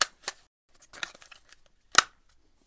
描述：金属香烟
Tag: 香烟 放大 金属 H4n